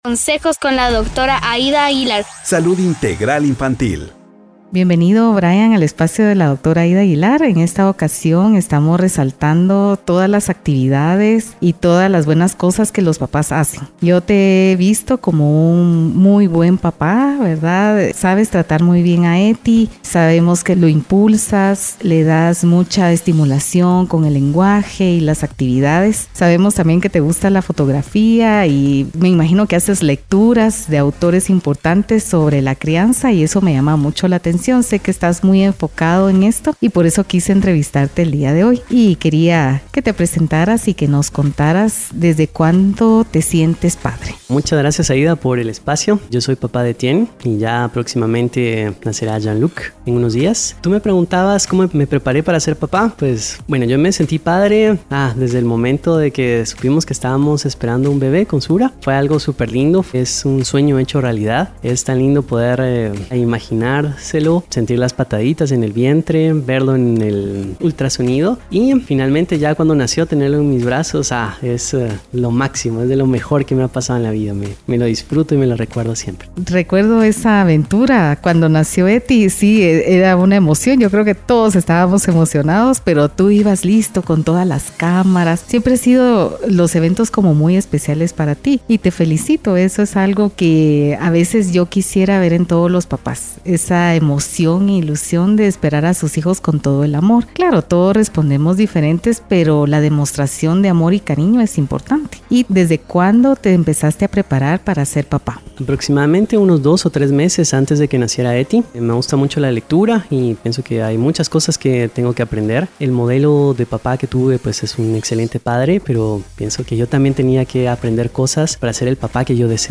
Celebrando a los ¡Súper Papás! Entrevista 2 Podcast #022